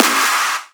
VEC3 Claps 023.wav